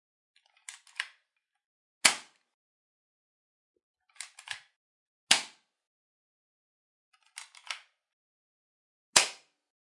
弹丸枪
描述：用Tascam DR40录制。记录在一个隔离的房间里。重弹和射击的弹丸枪的声音。